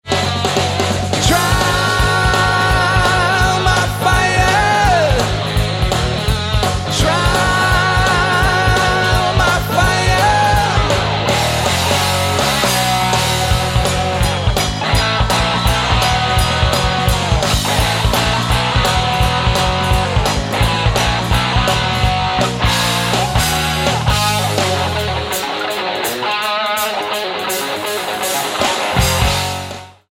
Album Notes: Recorded live 2000-2003